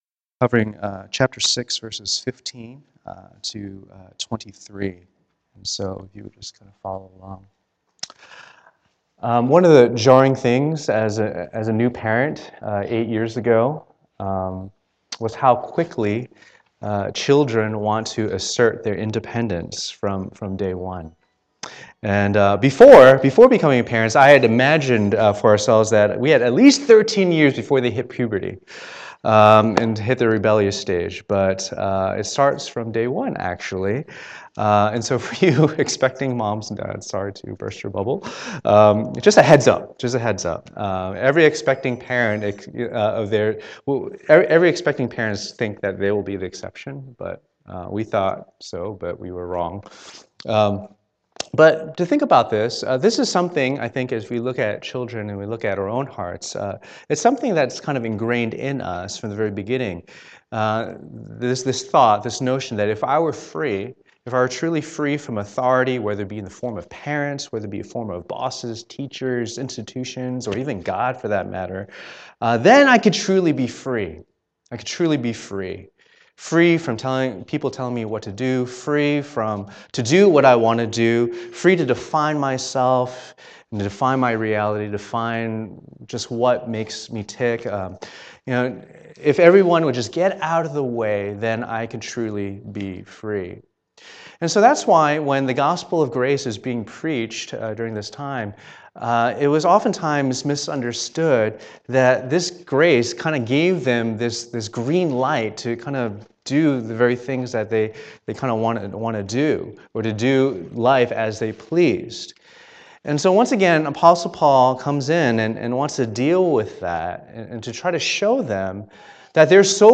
Romans 6:15-23 Service Type: Lord's Day %todo_render% « What is the gospel?